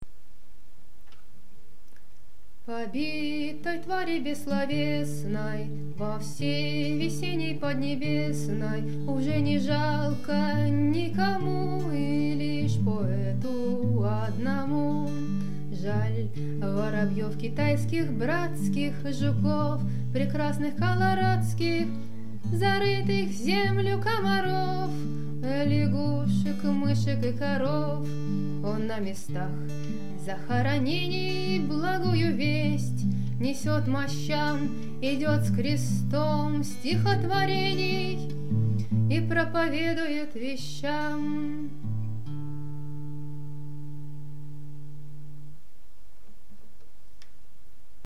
записано o3.04.2012 в Бергене, Норвегия